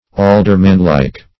Aldermanlike \Al`der*man*like`\, a. Like or suited to an alderman.